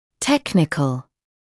[‘teknɪkl][‘тэкникл]технический